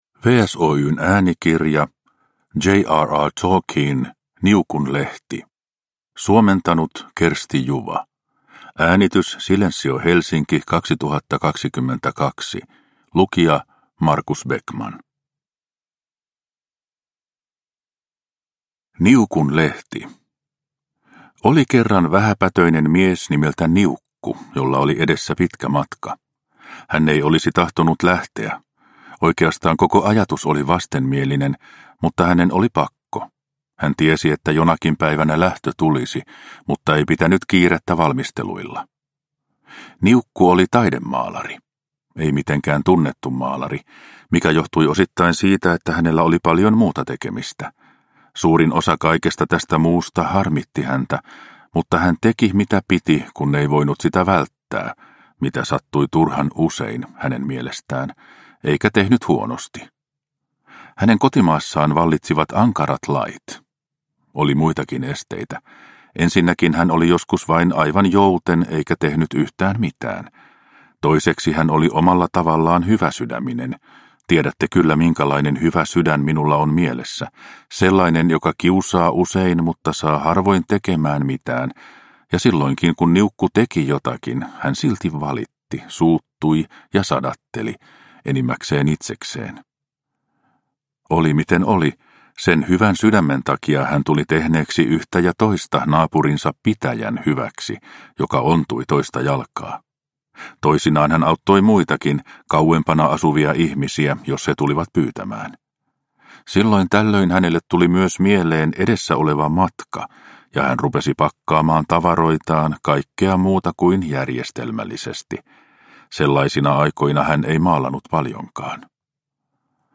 Niukun lehti – Ljudbok